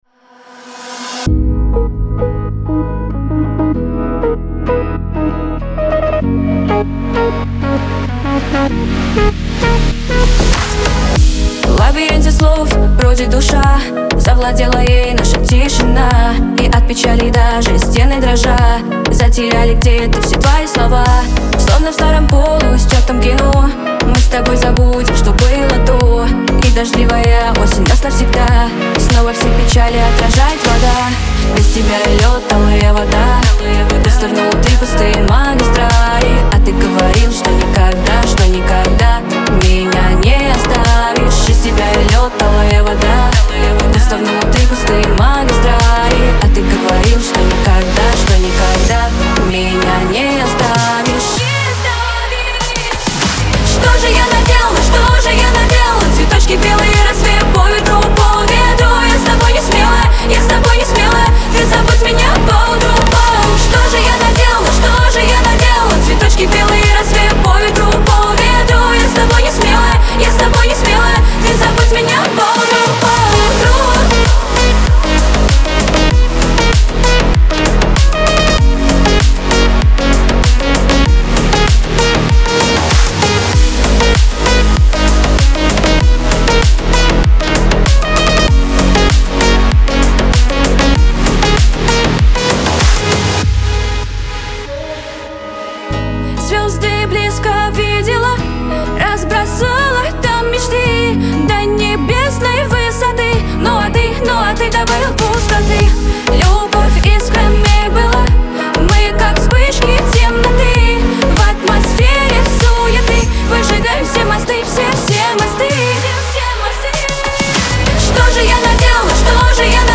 Лирика
грусть
pop